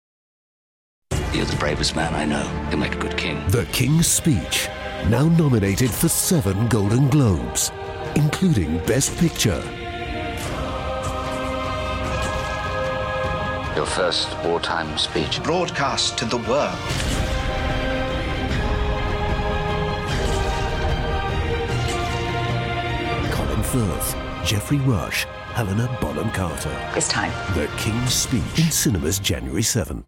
kingsspeechtv2.mp3